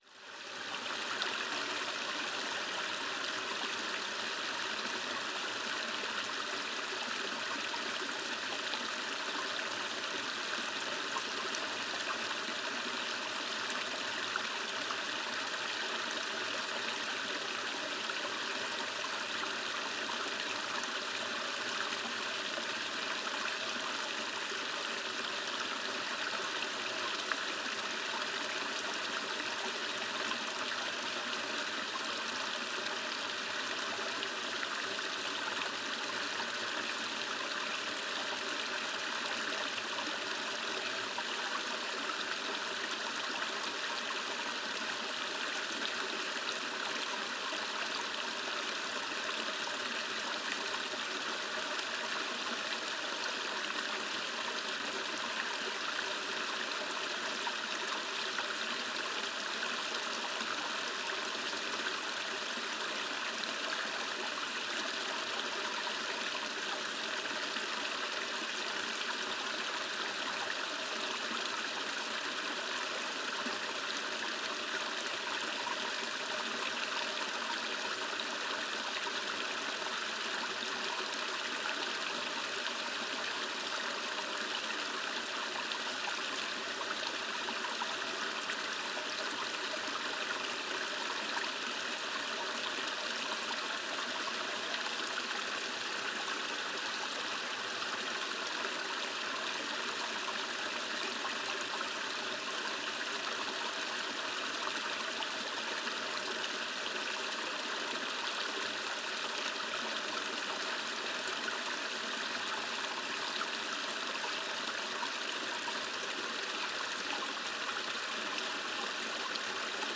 Soft Flowing Water From A Hidden Mountain Stream - Sleep Relax Meditate Peace Calm | Our MP3 Collection Of The Natural World Is Free To Download For Personal Use
Flowing Water Sounds - Zen Stream | Free MP3 Download
Everyone loves a Zen garden for its water features so why not let the sound of soft flowing water help you sleep, relax, study or medidate with this natural Zen stream?
running-water-sounds-stream-zen-10m.mp3